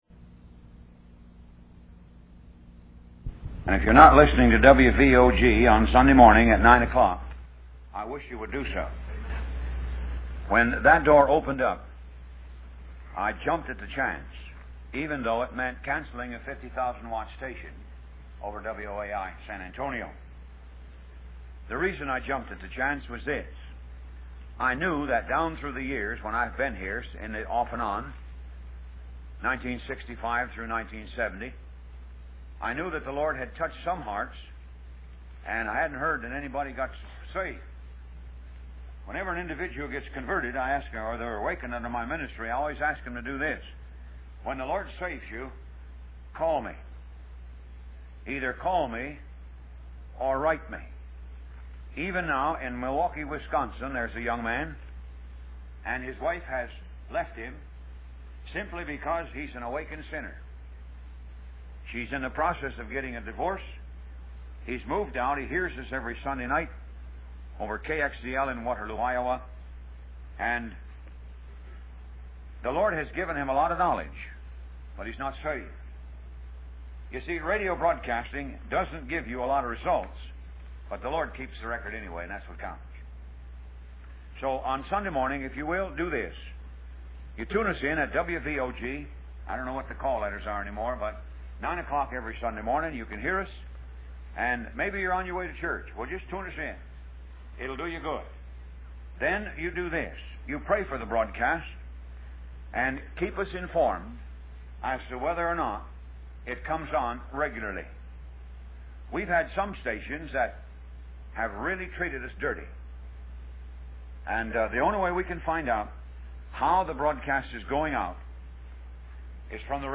Talk Show Episode, Audio Podcast, Moga - Mercies Of God Association and The Three Resurrections on , show guests , about The Three Resurrections, categorized as Health & Lifestyle,History,Love & Relationships,Philosophy,Psychology,Christianity,Inspirational,Motivational,Society and Culture